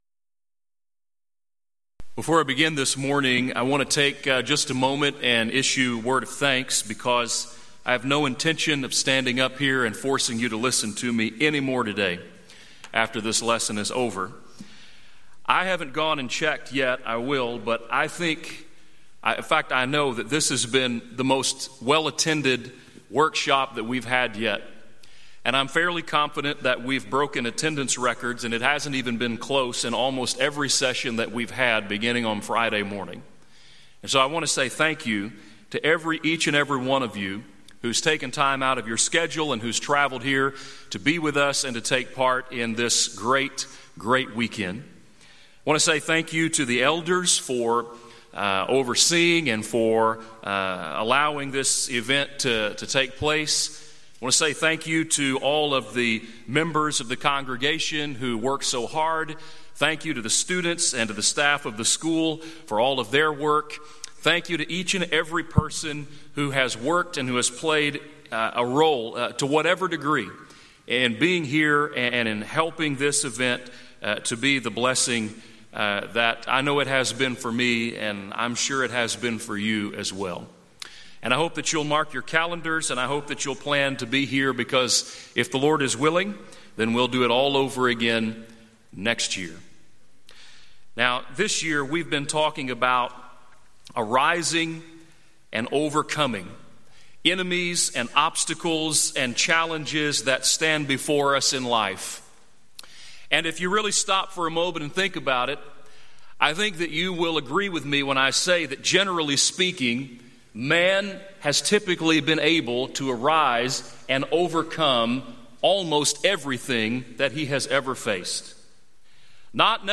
6th Annual Southwest Spiritual Growth Workshop
lecture